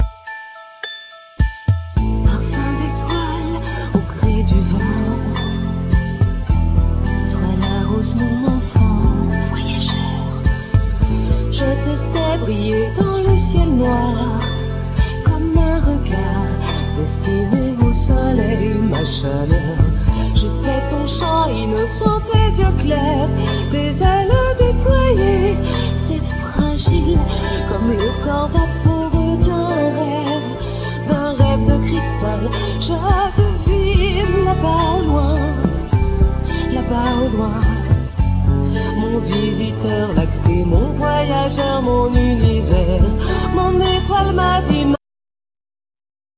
Piano
Hurdy Gurdy
Guitar,Base,Sequencers